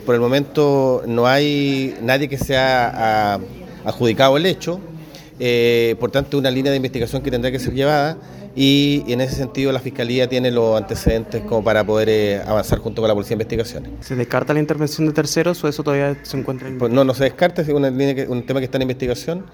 Consultado tras el comité policial de los días lunes, el delegado Presidencial, Jorge Alvial, indicó que la investigación sigue en desarrollo y confirmó que dos máquinas terminaron incendiadas y la tercera tuvo daños menores.